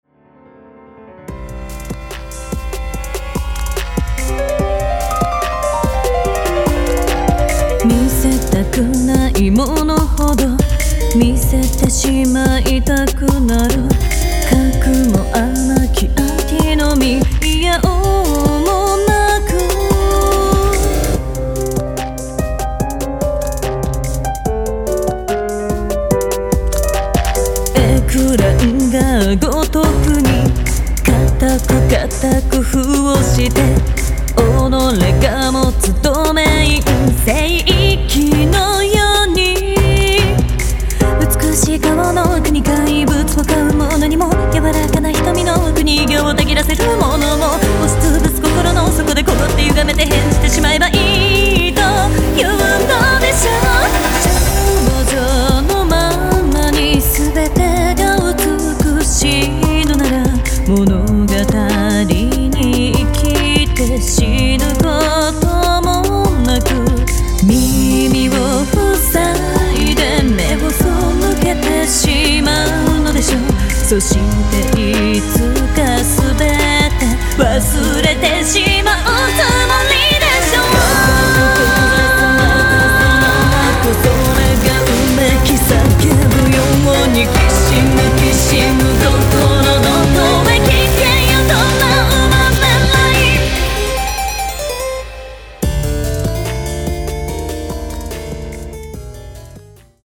東方マルチジャンルアレンジアルバムです。
Vocal